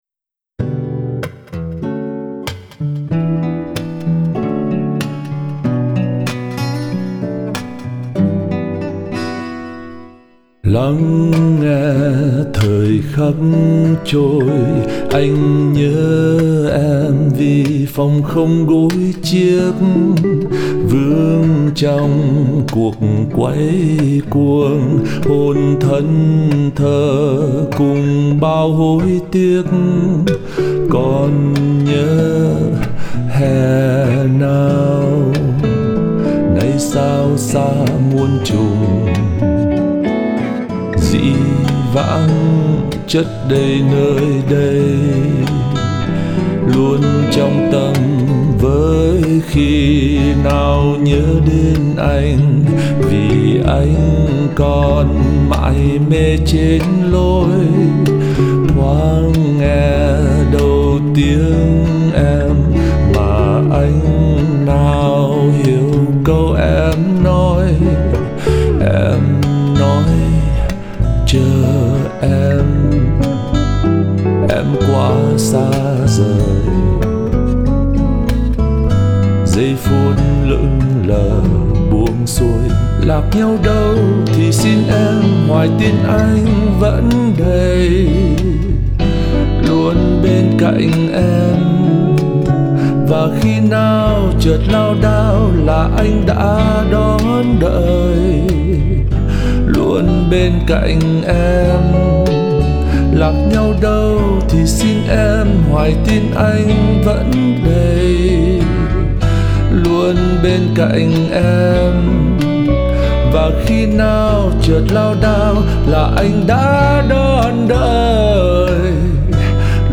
Cái chất pop rock của bài là điểm đặc thù của nó.